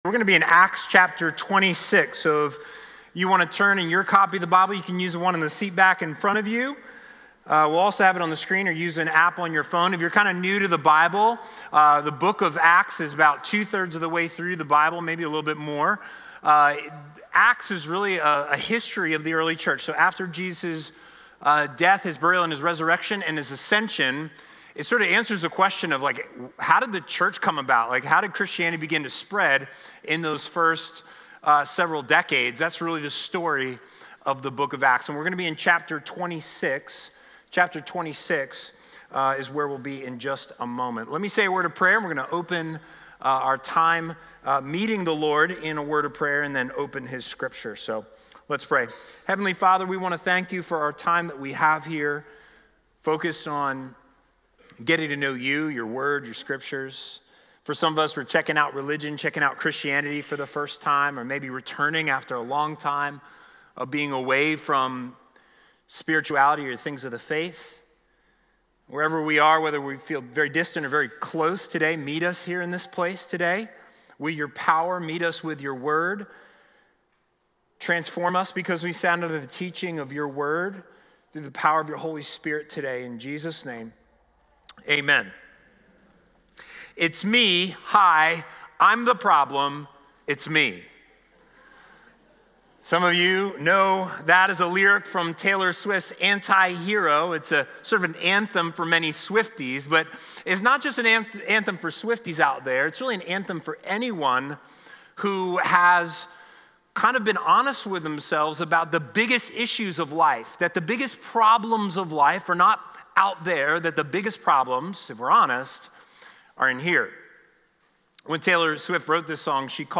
Communion will be served during the service.